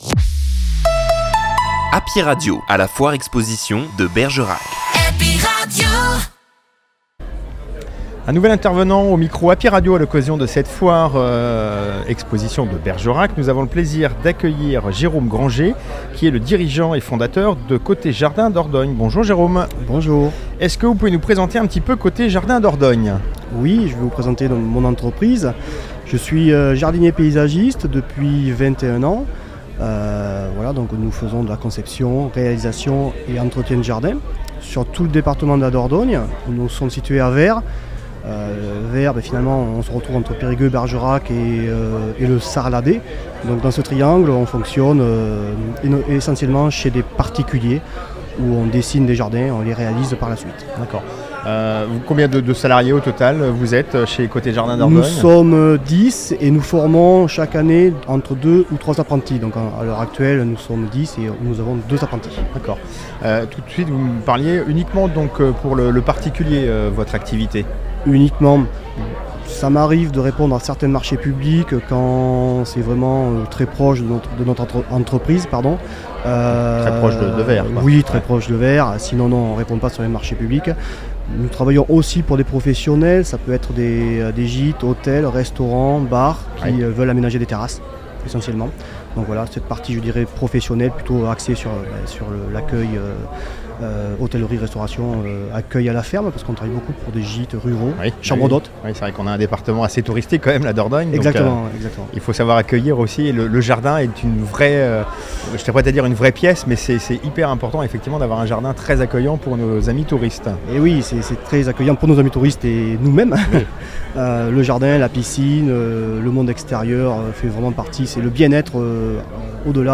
Foire Expo De Bergerac 2024